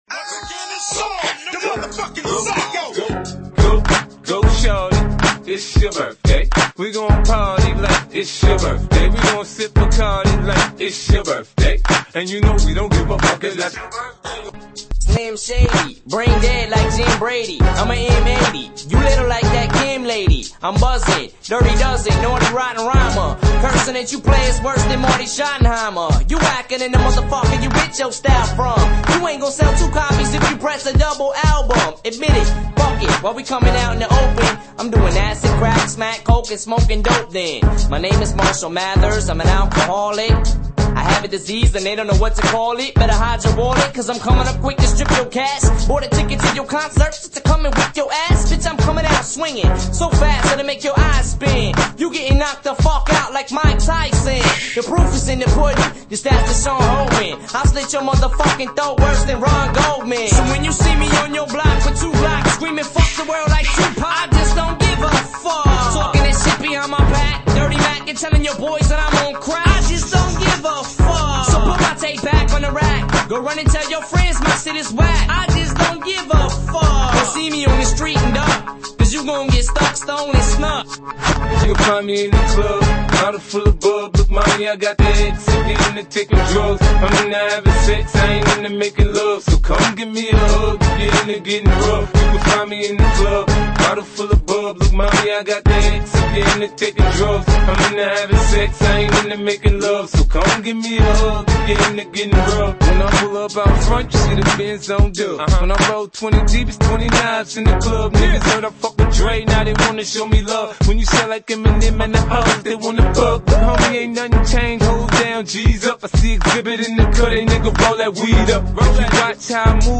Rap/Hip Hop [49]